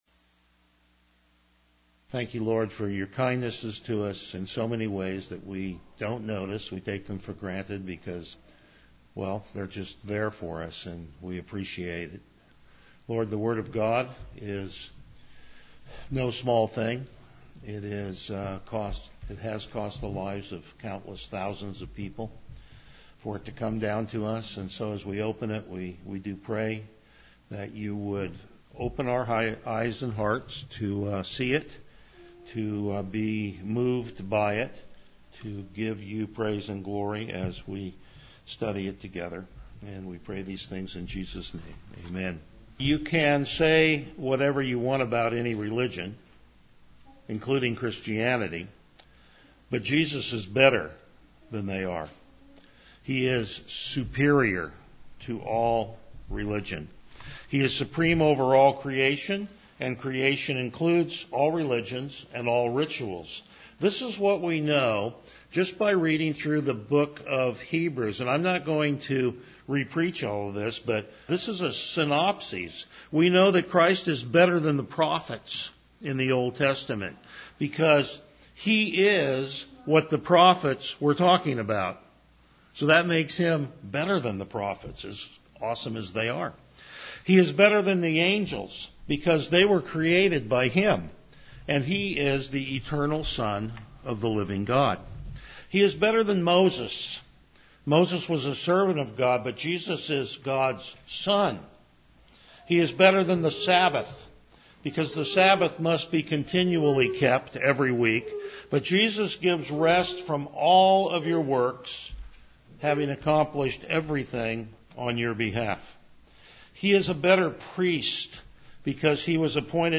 Hebrews 8:6-13 Service Type: Morning Worship How Jesus keeps the covenant for us!